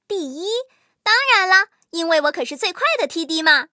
M18地狱猫MVP语音.OGG